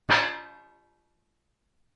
金属 " 干式冲击击中金属板
描述：用索尼PCMD50录制的。 撞到金属物体。锈板的形式。
标签： 冲击 击打 金属 命中
声道立体声